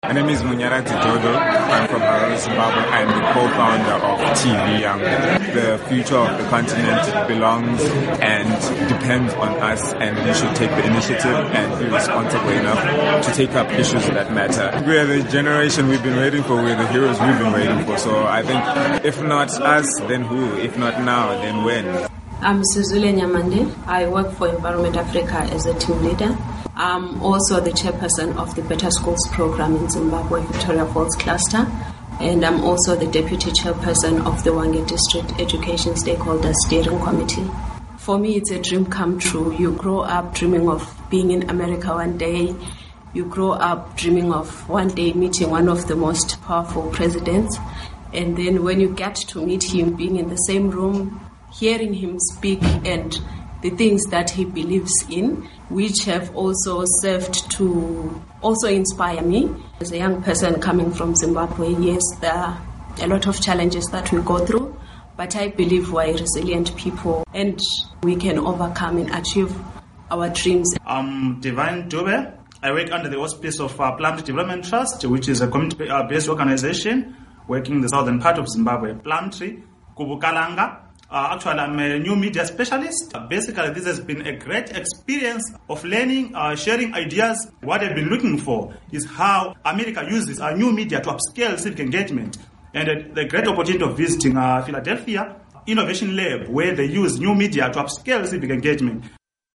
Interview with Yali Fellows